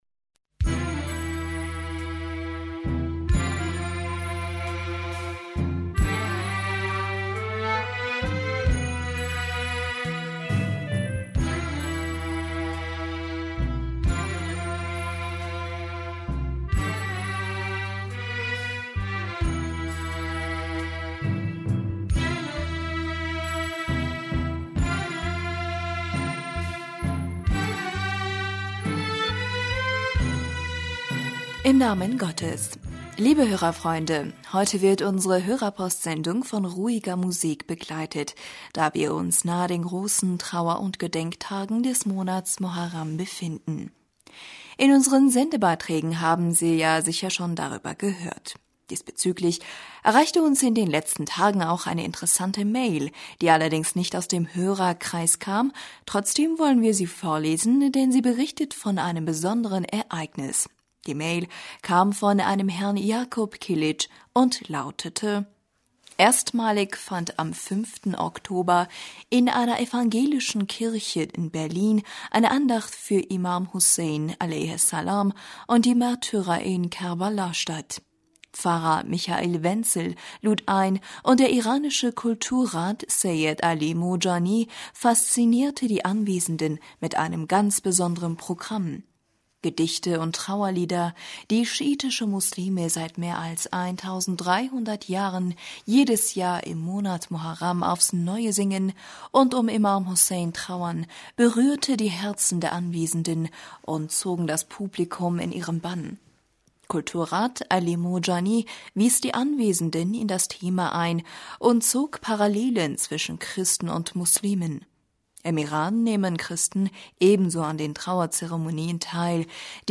Hörerpostsendung am 9.Oktober 2016 - Bismillaher rahmaner rahim - Liebe Hörerfreunde, heute wird unsere Hörerpostsendung von ruhiger Musik begleite...
Liebe Hörerfreunde, heute wird unsere Hörerpostsendung von ruhiger Musik begleitet, da wir uns nahe den großen Trauer- und Gedenktagen des Monats Muharram befinden.